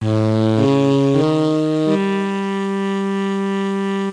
horn1.mp3